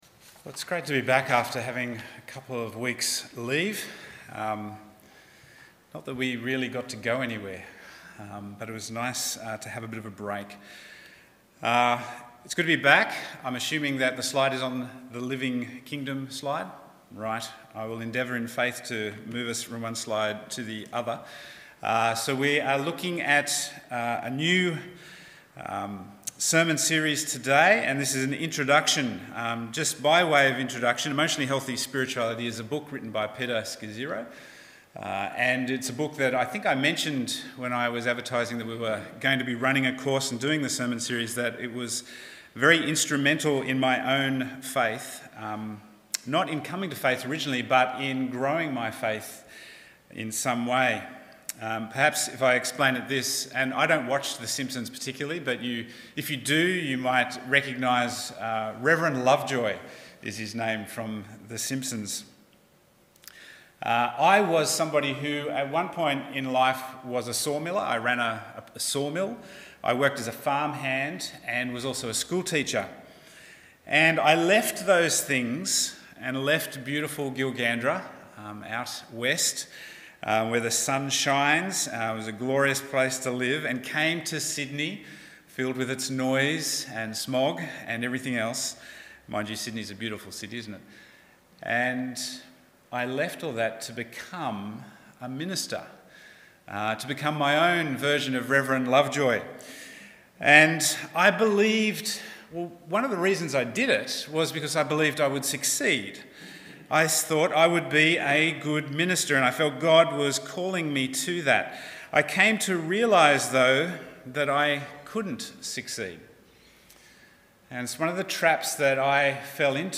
Bible Text: 2 Corinthians 5:14-21 | Preacher